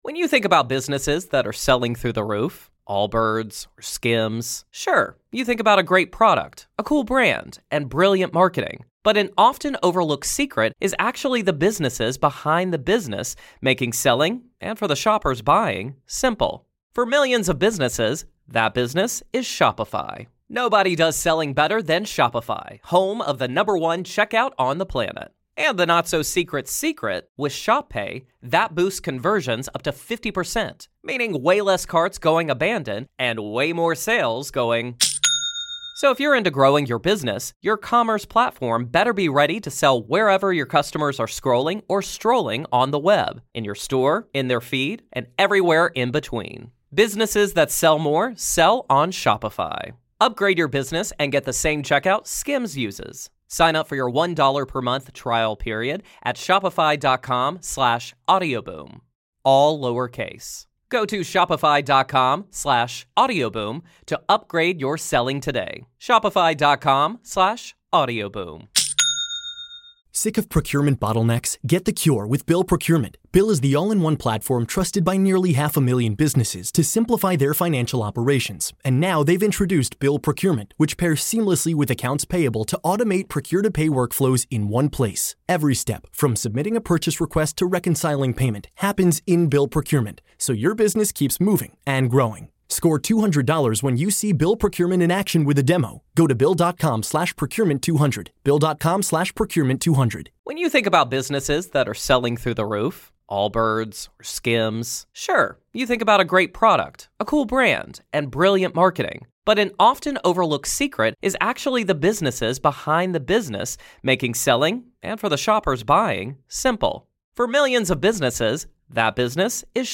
A truly wide-ranging conversation that connects human potential, intuition, and the mysteries of the cosmos.